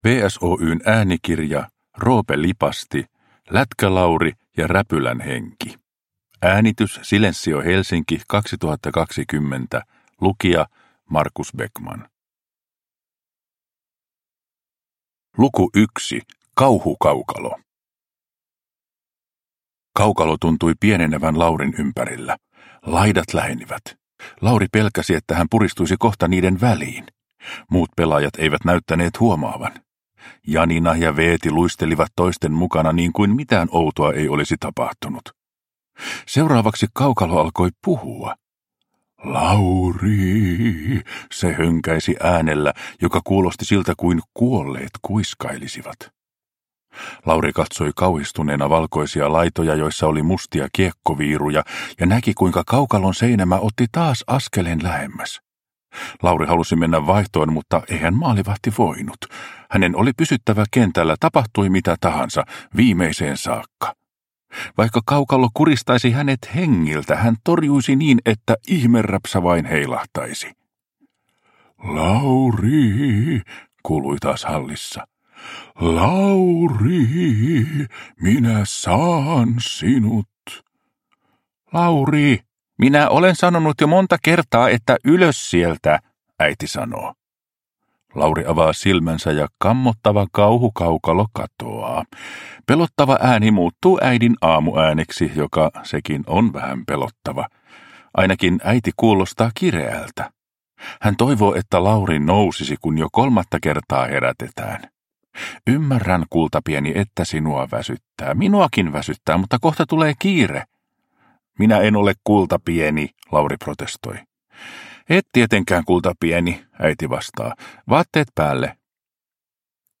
Lätkä-Lauri ja räpylän henki – Ljudbok – Laddas ner